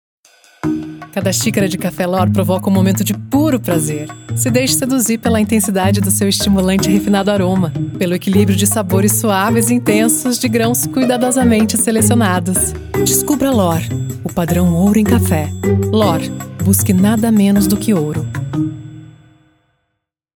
Loc. Distante: